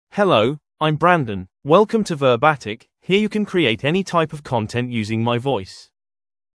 MaleEnglish (United Kingdom)
BrandonMale English AI voice
Voice sample
Male
Brandon delivers clear pronunciation with authentic United Kingdom English intonation, making your content sound professionally produced.